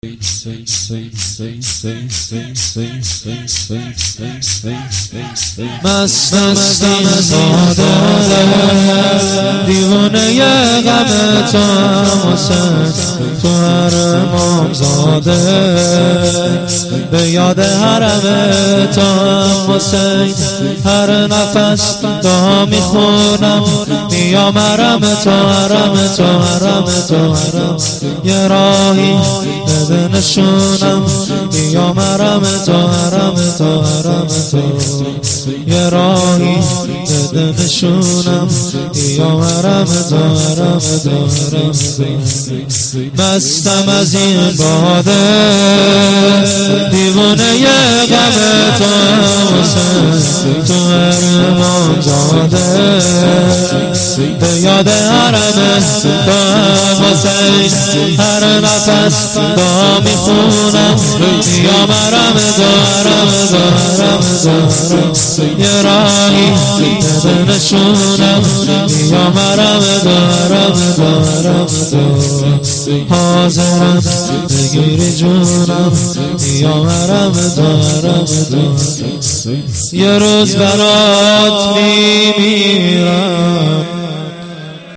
شور - شهادت حضرت میثم تمار ره
مداح